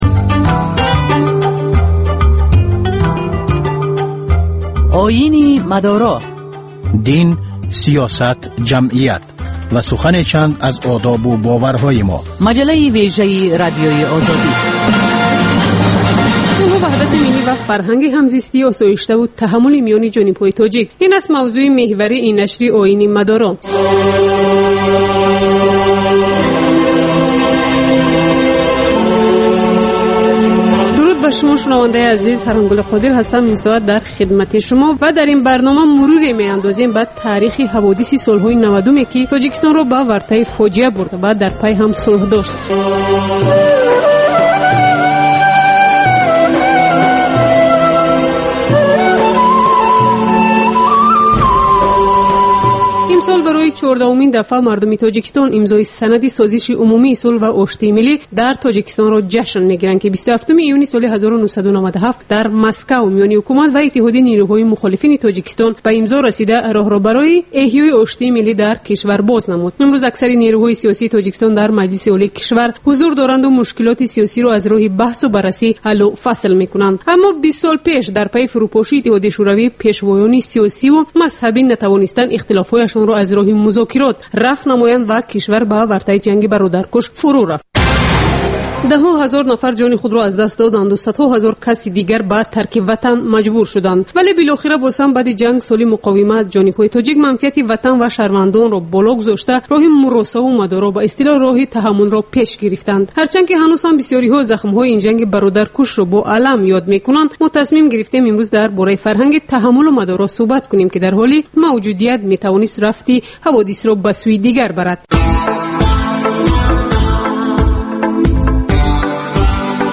Дин ва ҷомеа. Гузориш, мусоҳиба, сӯҳбатҳои мизи гирд дар бораи муносибати давлат ва дин. Шарҳи фатво ва нукоти мазҳабӣ.